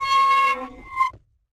gate sound
gate.ogg